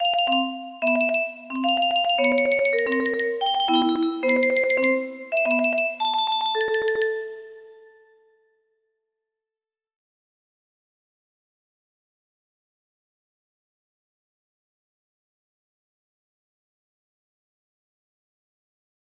drammatico music box melody